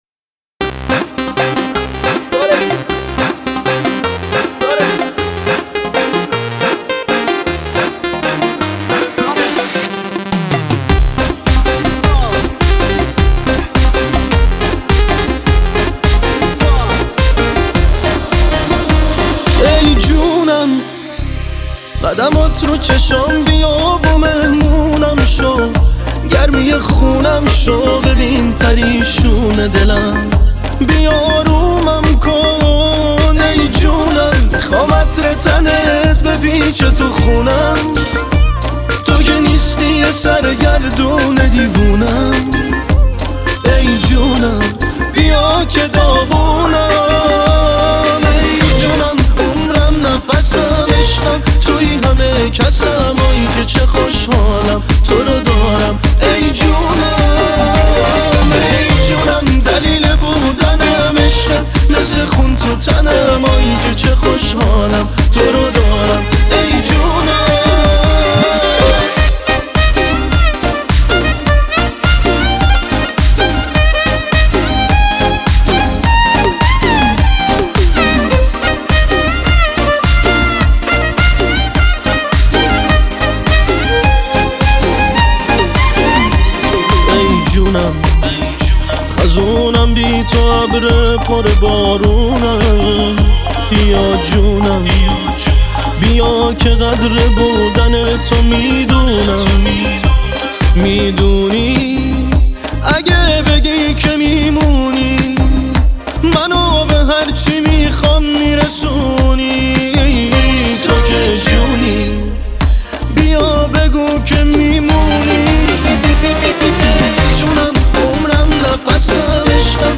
دانلود آهنگ شاد